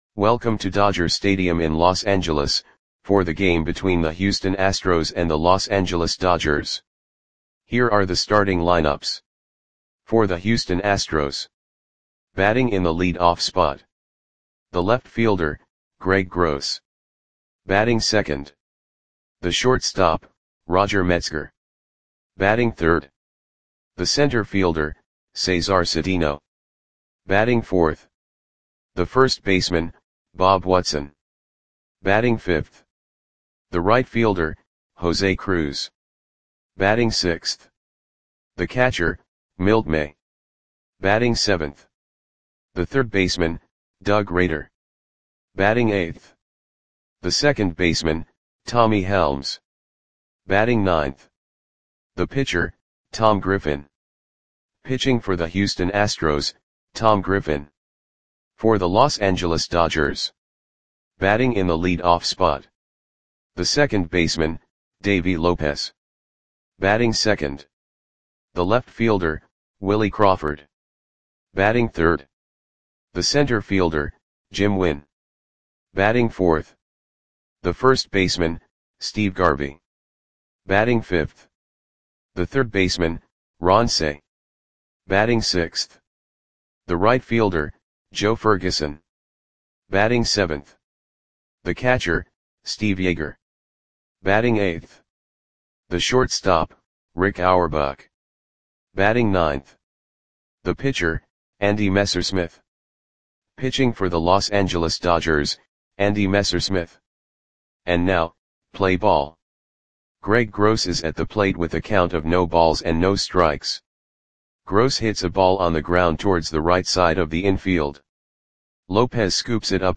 Lineups for the Los Angeles Dodgers versus Houston Astros baseball game on May 7, 1975 at Dodger Stadium (Los Angeles, CA).
Click the button below to listen to the audio play-by-play.